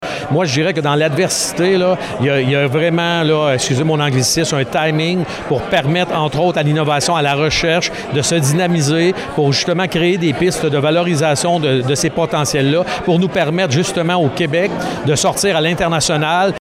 Lors d’une conférence de presse jeudi, l’organisme a cité ce risque parmi les défis qu’il devra relever.